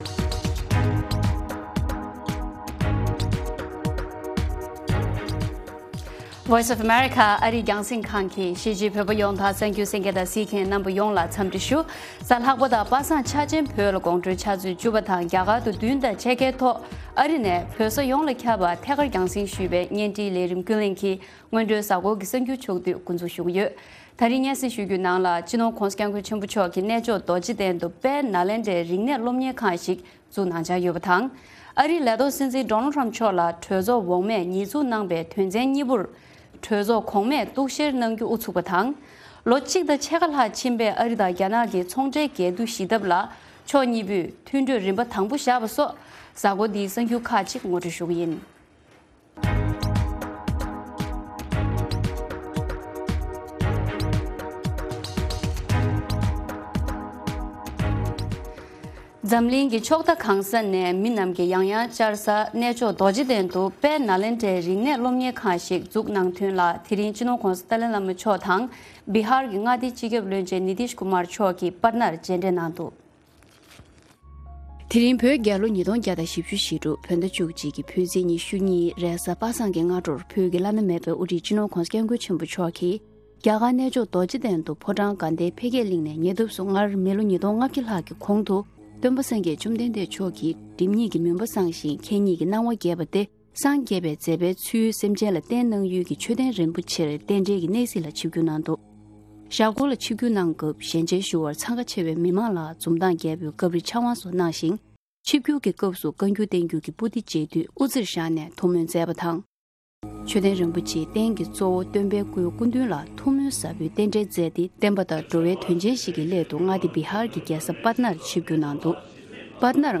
Good Evening Tibet Broadcast daily at 10:00 PM Tibet time, the Evening Show presents the latest regional and world news, correspondent reports, and interviews with various newsmakers and on location informants. Weekly features include Tibetan Current Affairs, Youth, Health, Buddhism and Culture, and shows on traditional and contemporary Tibetan music.